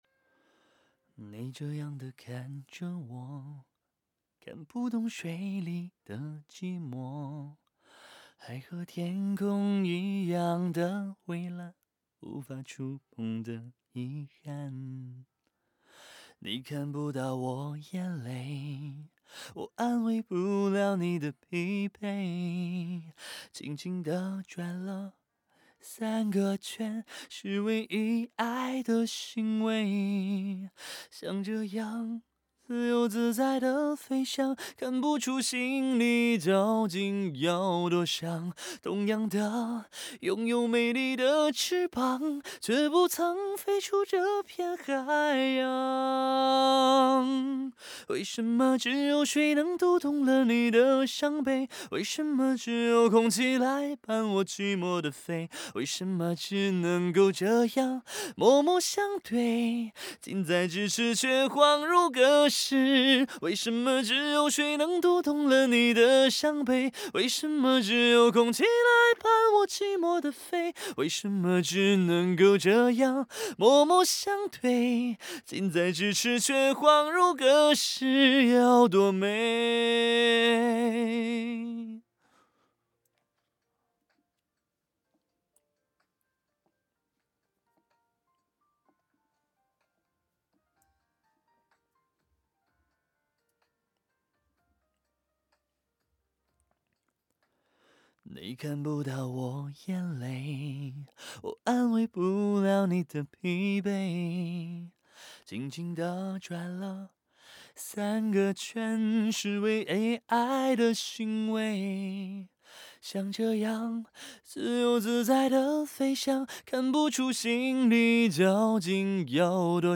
M3录人声（不能右键另存为，必须点击后在线收听）
人声肯定是不如u87舒服的，毕竟价钱差别太大，但是m3录制的人声比一般的小振膜要暖一点，中频的相应也不错，不像NT3那么硬，细节方面也是不错的。